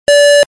beep.mp3